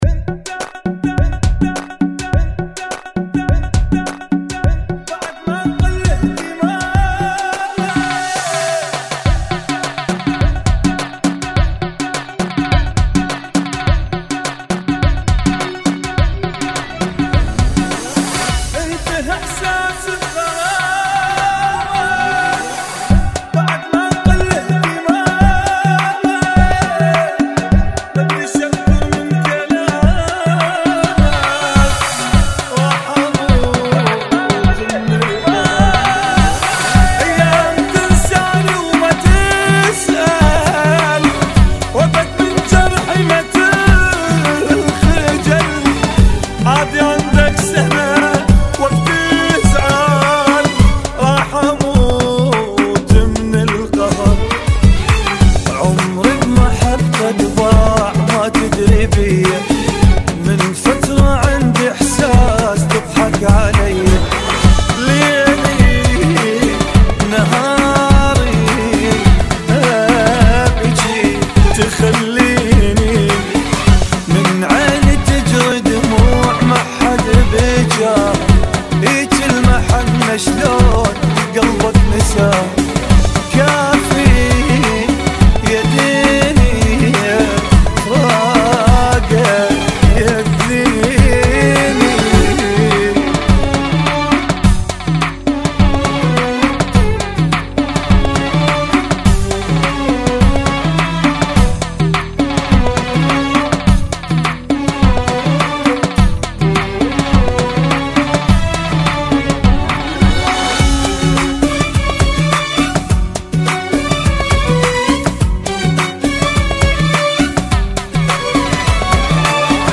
Funky [ 104 Bpm